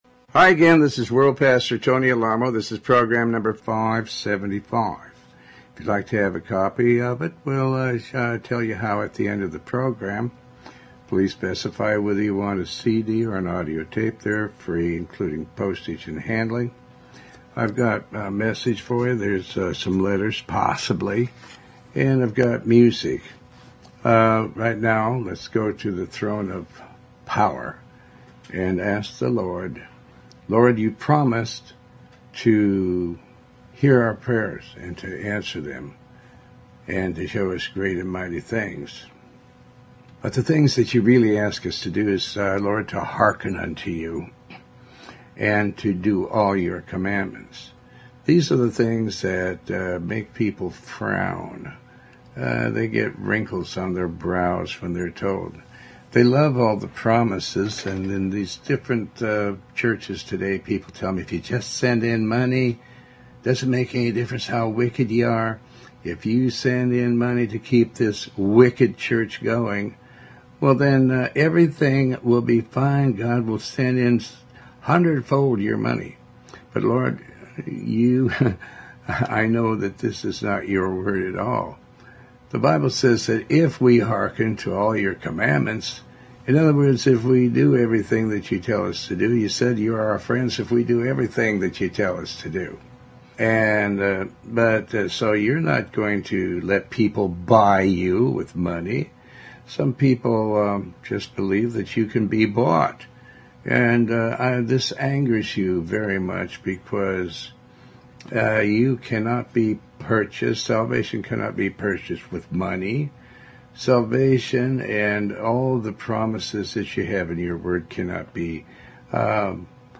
Talk Show Episode, Audio Podcast, Tony Alamo and God is the Law.